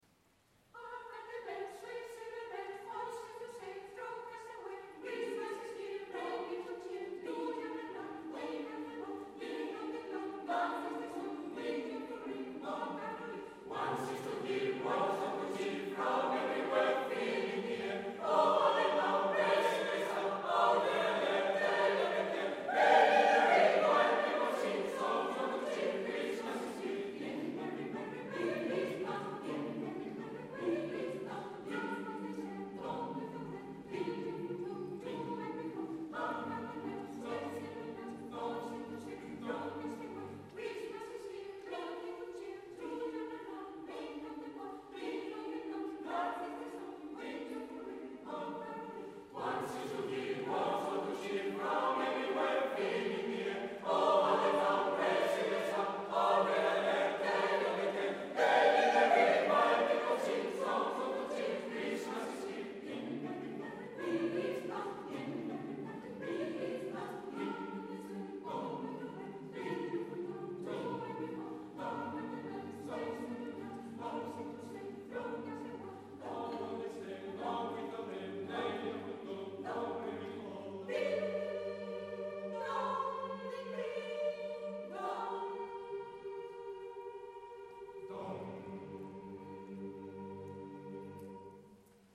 en concierto